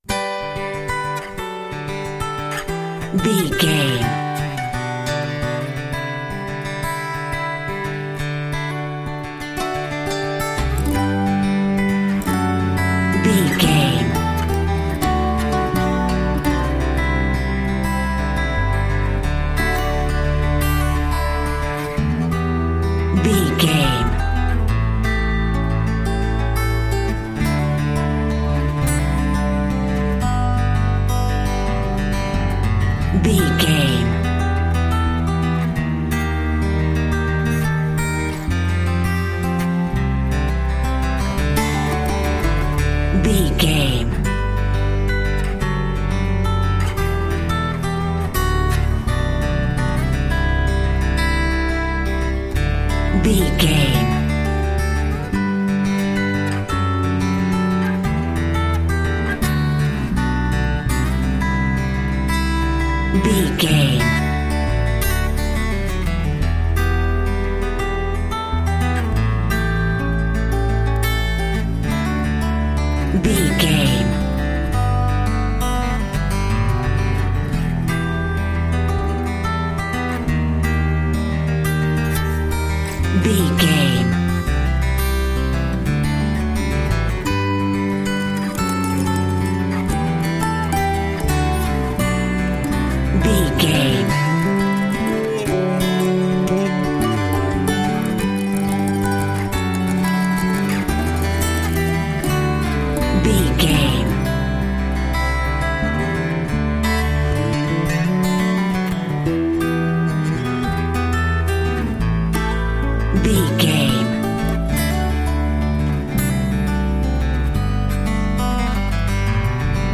Ionian/Major
acoustic guitar
bass guitar